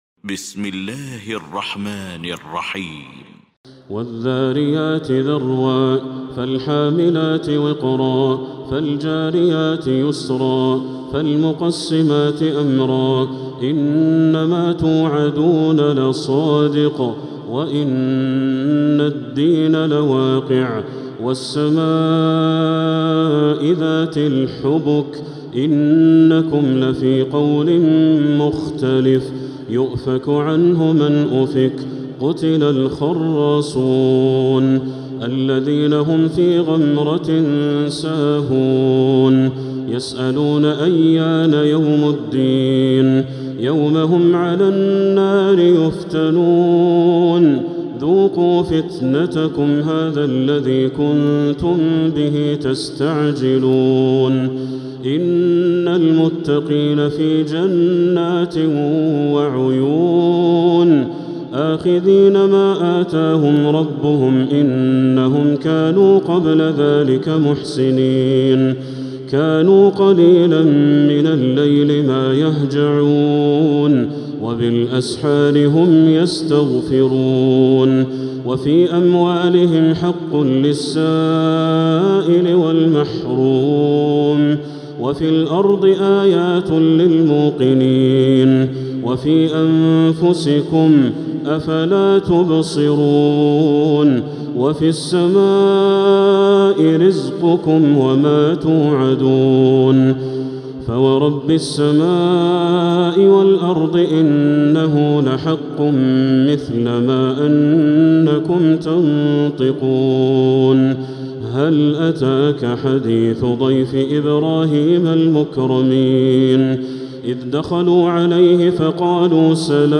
المكان: المسجد الحرام الشيخ: بدر التركي بدر التركي الذاريات